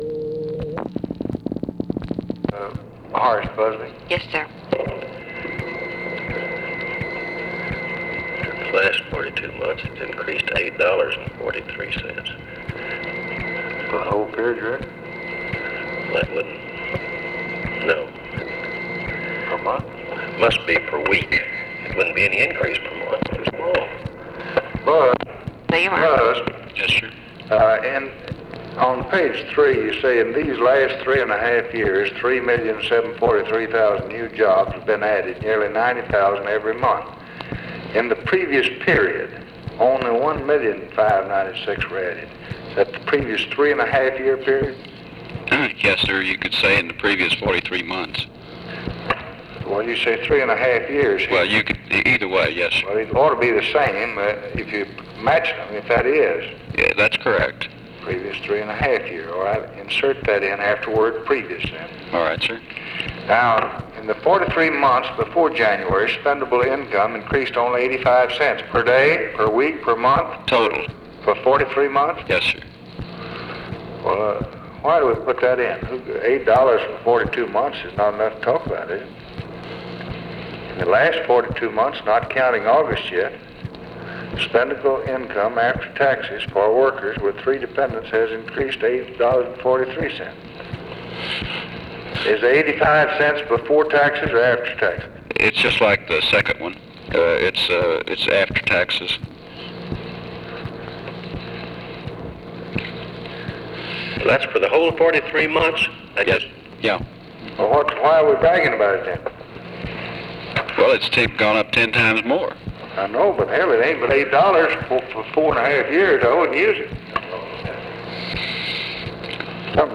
Conversation with HORACE BUSBY and WALTER JENKINS, September 14, 1964
Secret White House Tapes